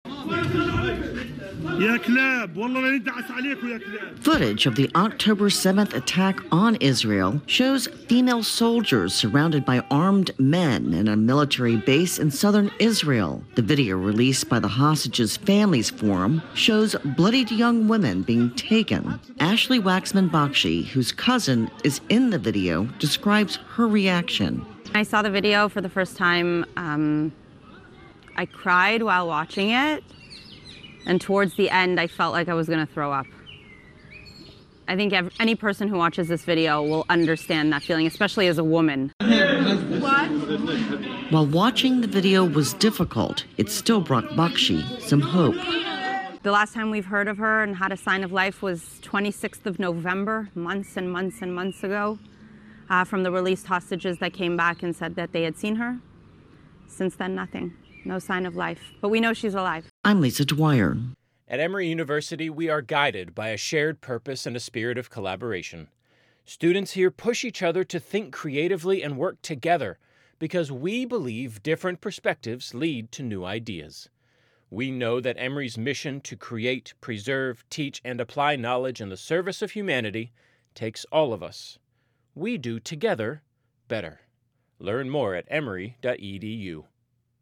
((Upsound: ARABIC: man shouting: "You dogs!